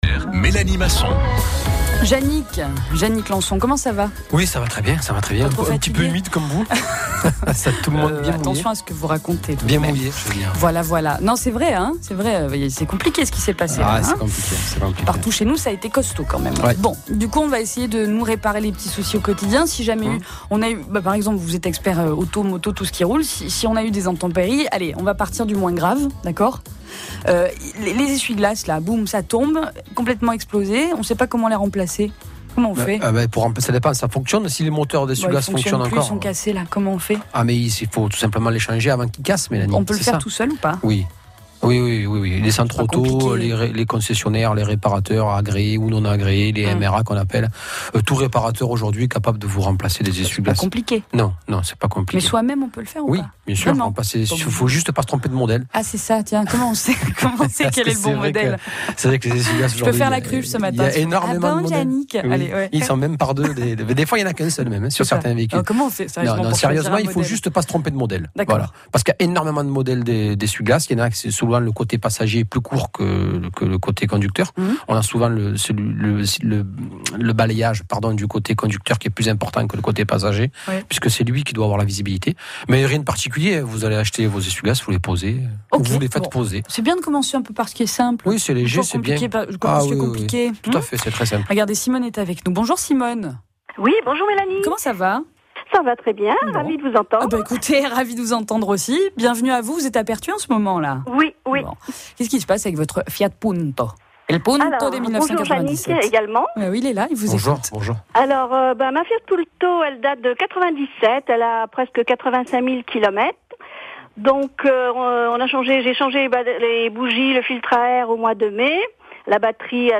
est régulièrement à l'antenne de France Bleu Provence Conseils automobiles et informations aux auditeurs.